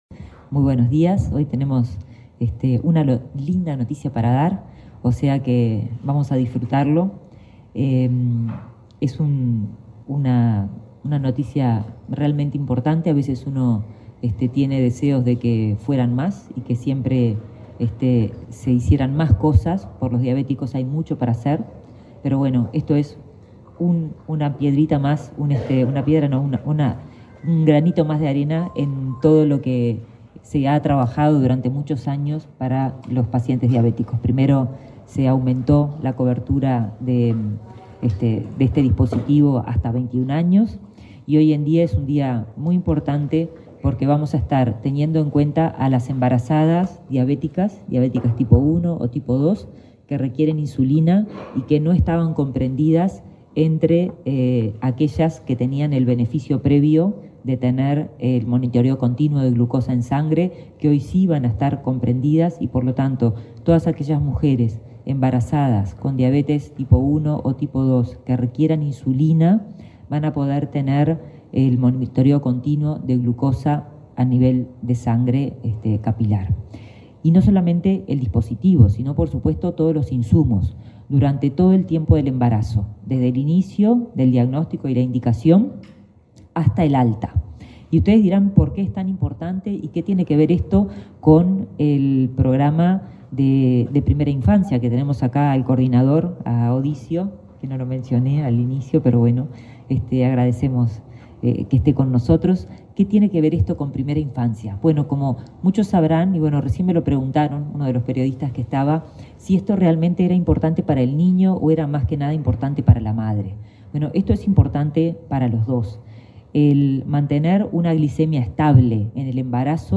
Palabras de autoridades en el MSP
Este viernes 2 en la sede del Ministerio de Salud Pública (MSP), la titular y el subsecretario de la cartera, Karina Rando y José Luis Satdjian,